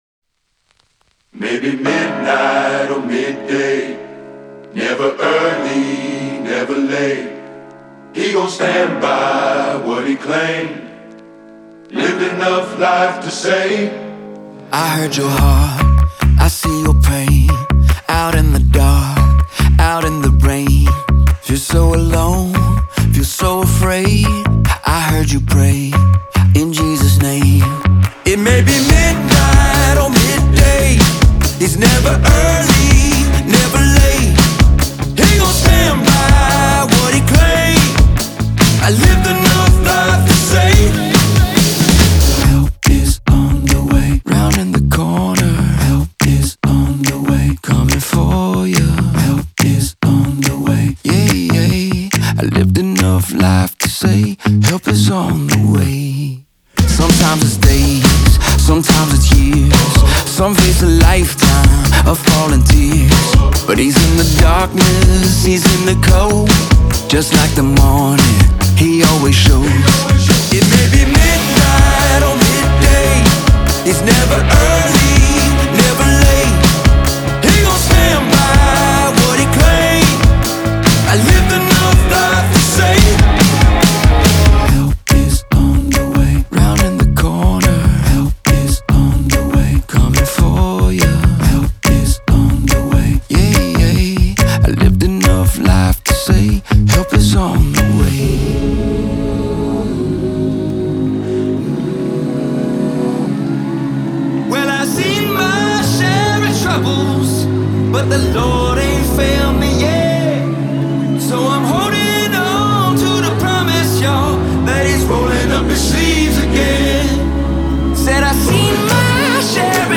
энергичная и вдохновляющая песня